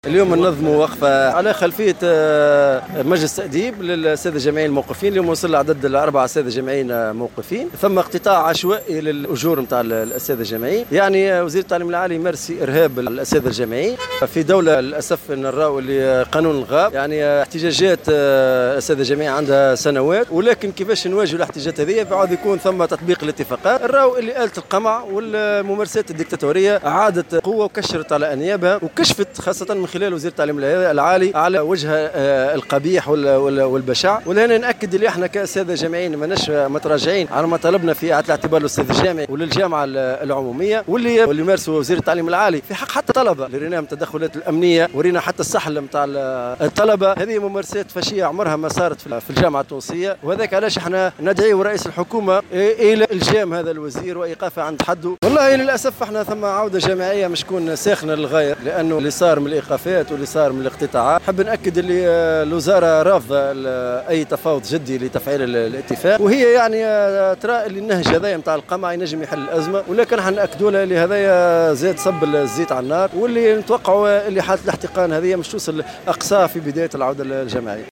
في تصريح اليوم لمراسل "الجوهرة أف أم" على هامش وقفة احتجاجية أمام مقر وزارة التعليم العالي